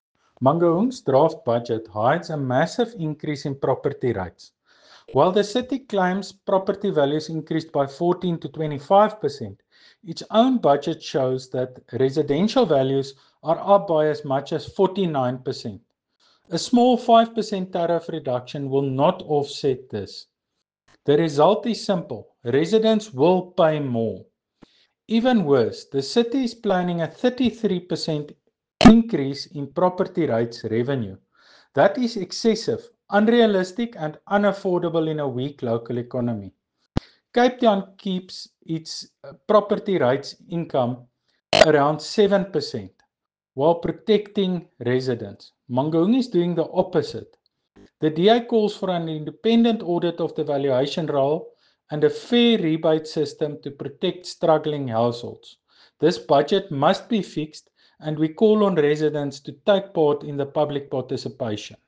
Afrikaans soundbites by Cllr Tjaart van der Walt and